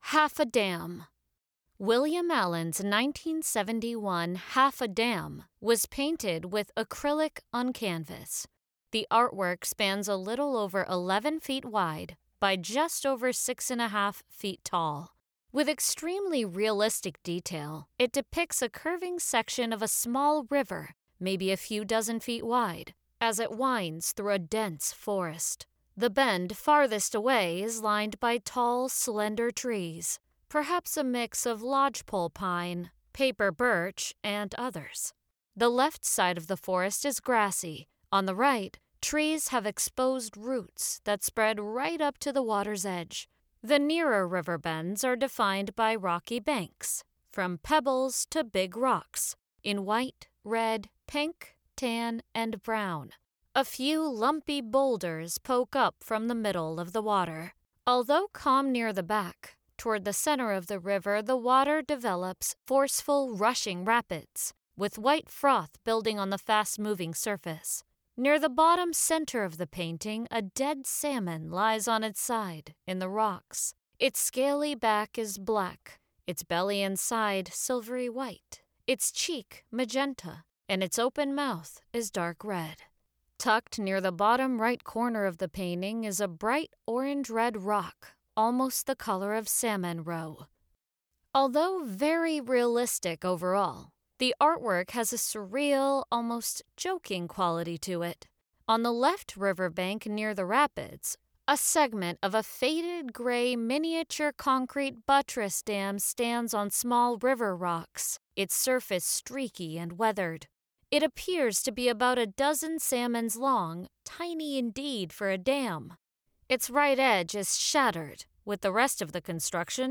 Audio Description (04:10)